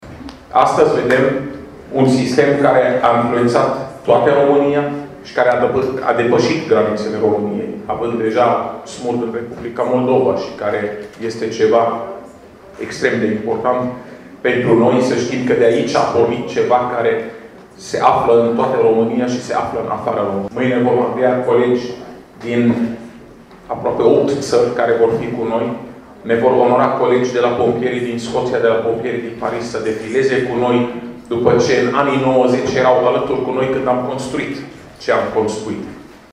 Fondatorul SMURD, Raed Arafat: